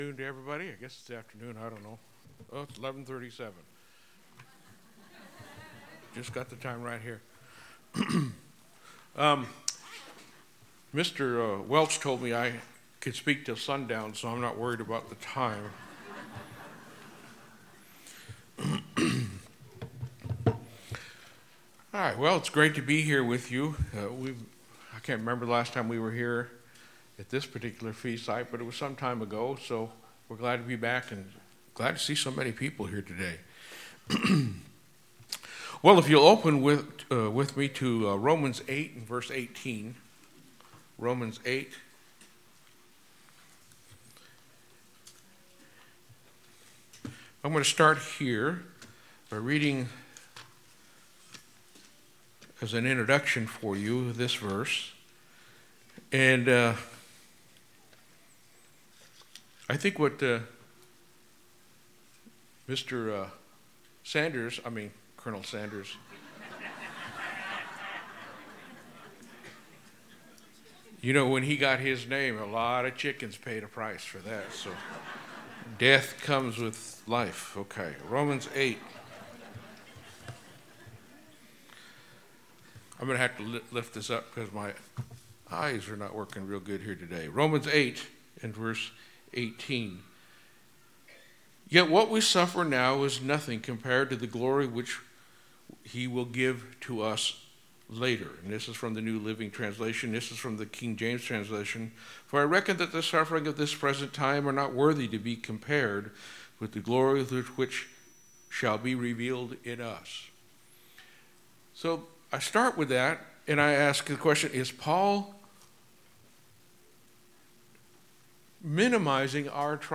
This sermon was given at the Galveston, Texas 2023 Feast site.